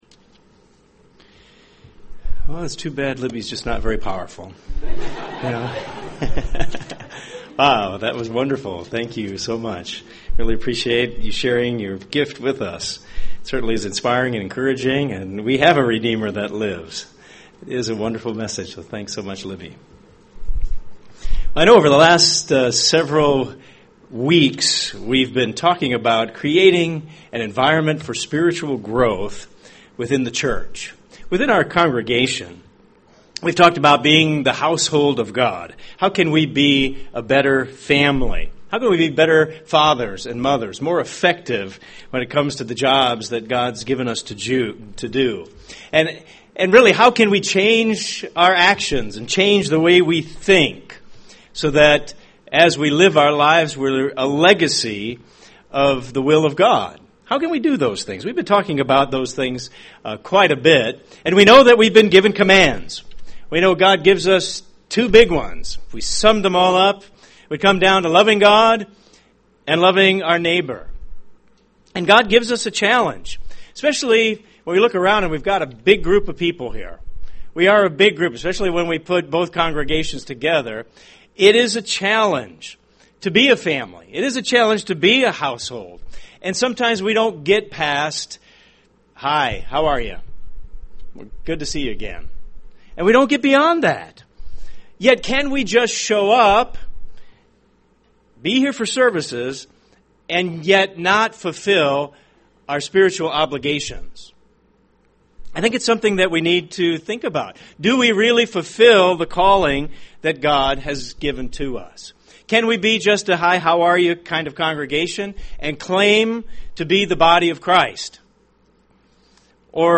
This sermon focuses on the importance of a healthy church congregation and how to accomplish that.